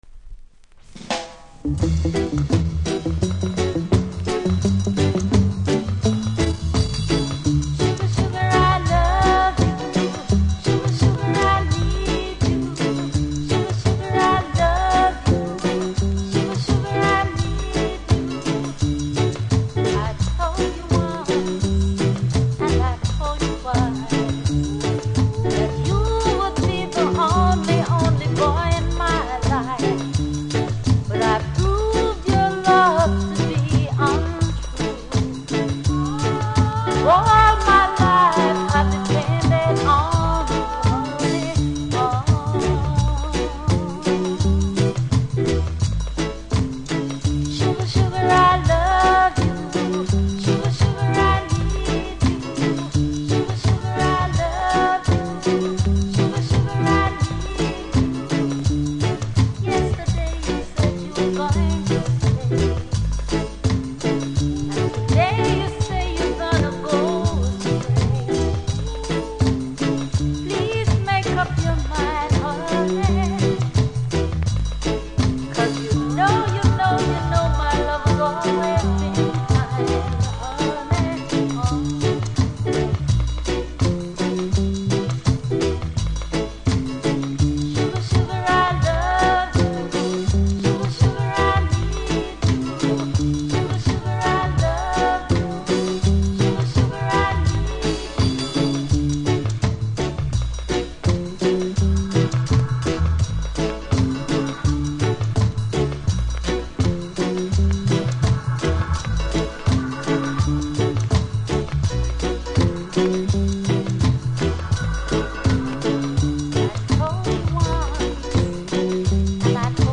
REGGAE 70'S
中盤小傷ありノイズ感じますので試聴で確認下さい。